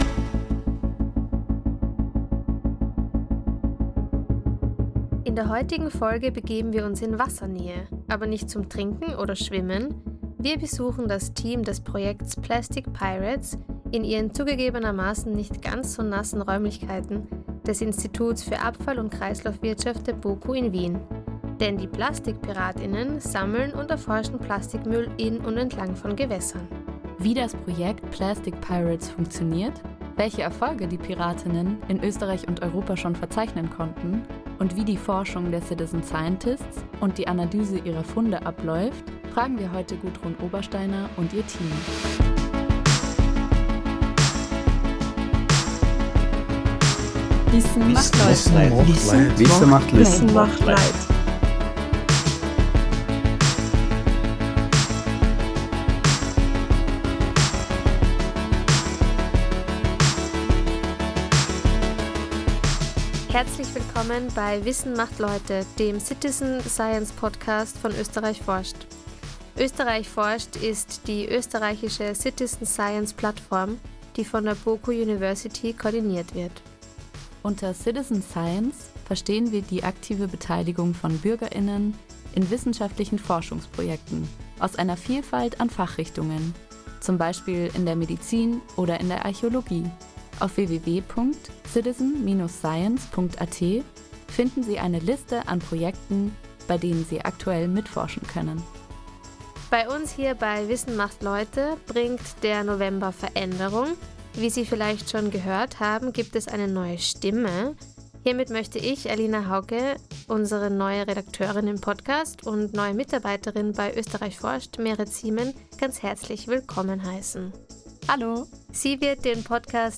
Für diese Folge haben wir das österreichische Team von Plastic Pirates am Institut für Abfall- und Kreislaufwirtschaft der BOKU in Wien besucht